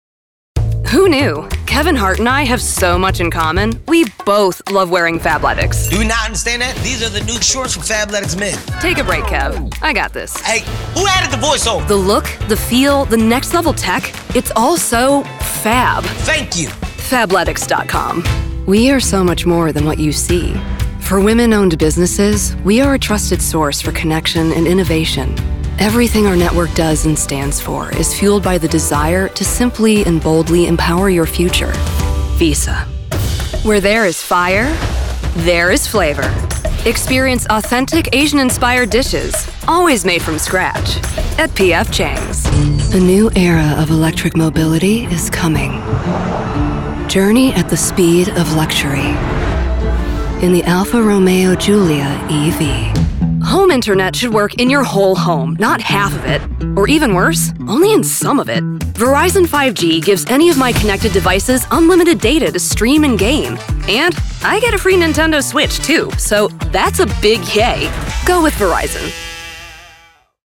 southern us | natural
standard british | character
standard us | natural
COMMERCIAL 💸